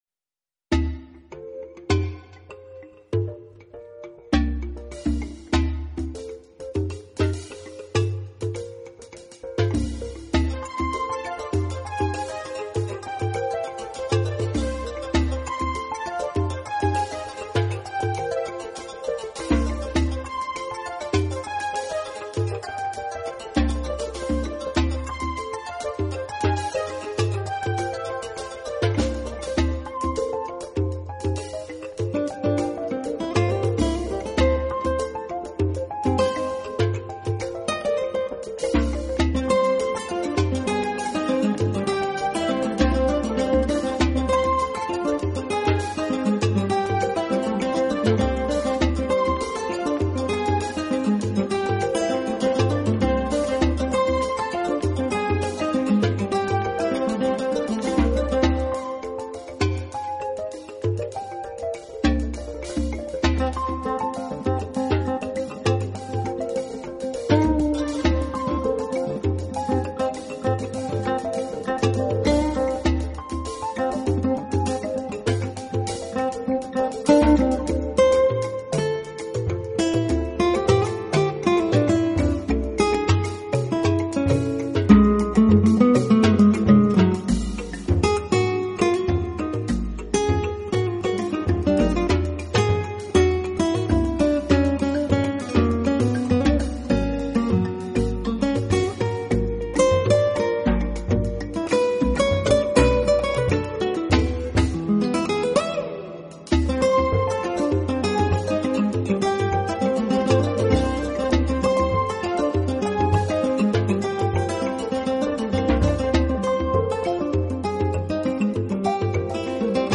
一张木吉他的独奏，不知道会让多少人联想到乏味与沉闷，也不知道会证明多少人的乏味
没有语言，没有其他，一把木吉他，是久违的音乐最原始的根基：交流。